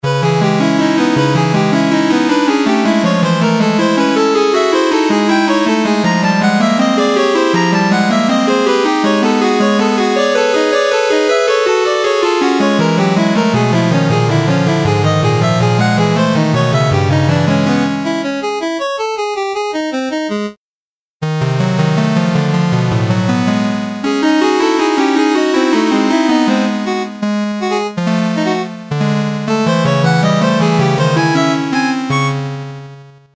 It does nintendo-y synth sounds with simple wav patterns.
Filed under: Instrumental | Comments (2)